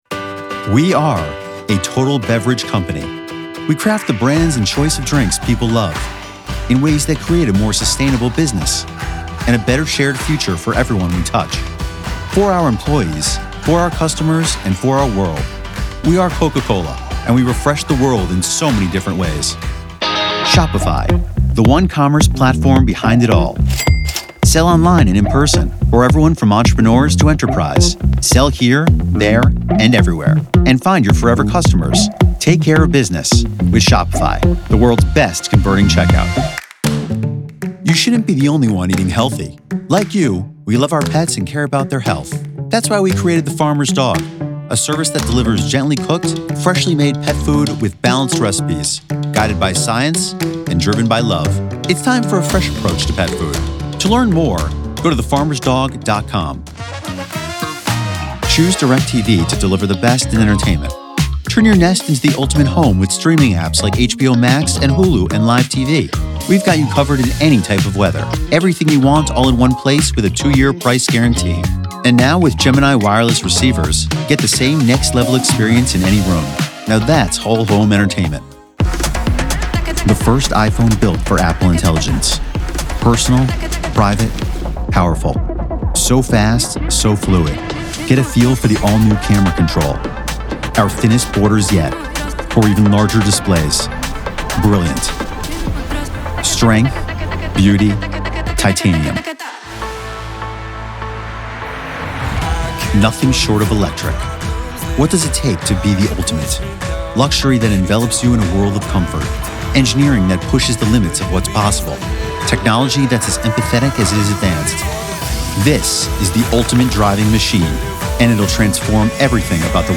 Browse professional voiceover demos.